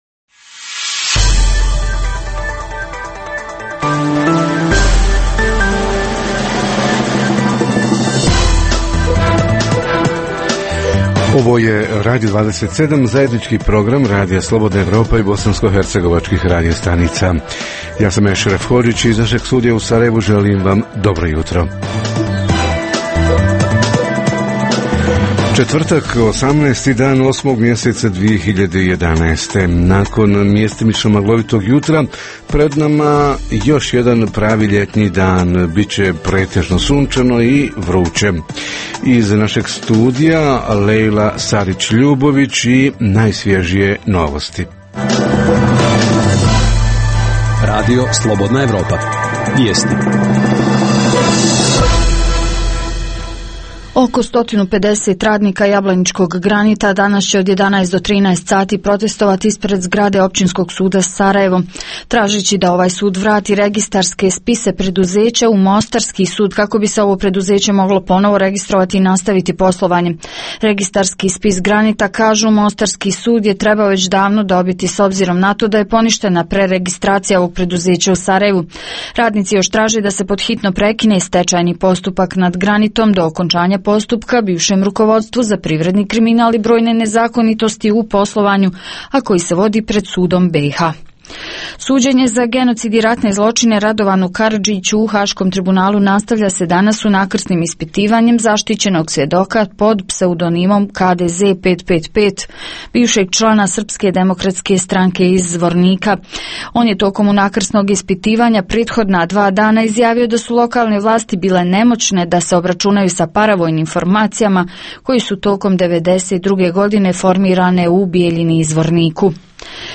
Tema jutarnjeg programa - koja svakog od nas sigurno zanima: pada cijena brašnu – hoće li i hljeb pojeftiniti? Reporteri iz cijele BiH javljaju o najaktuelnijim događajima u njihovim sredinama.
Redovni sadržaji jutarnjeg programa za BiH su i vijesti i muzika.